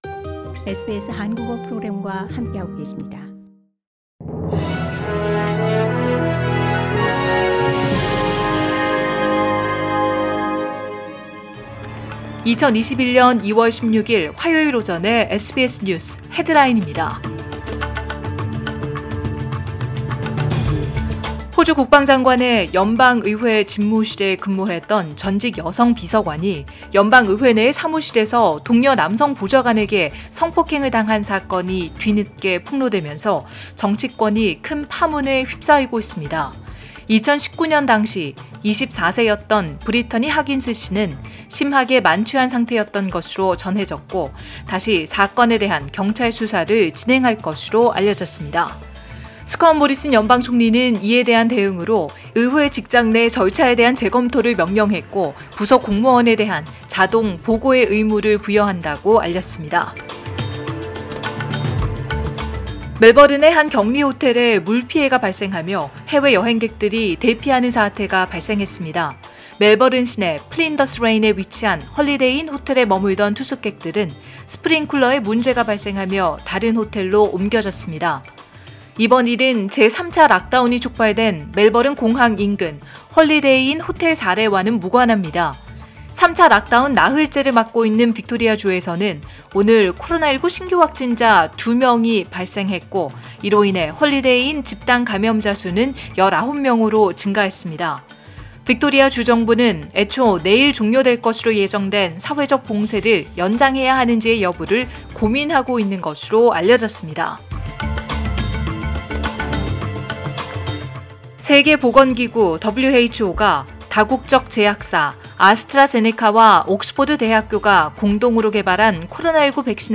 2021년 2월 16일 화요일 오전의 SBS 뉴스 헤드라인입니다.